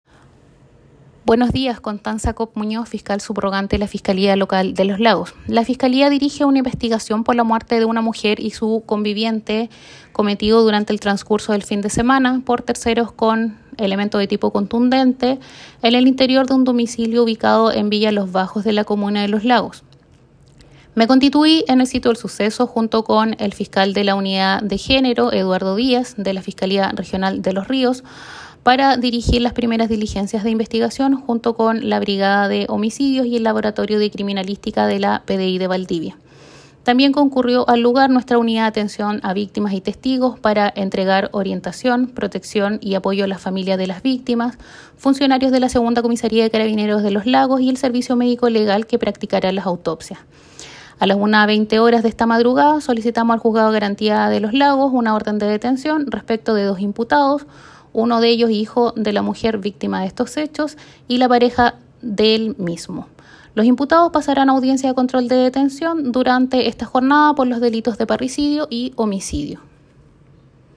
Cuña de la fiscal subrogante de Los Lagos, Constanza Kopp